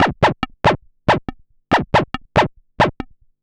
tx_perc_140_barking.wav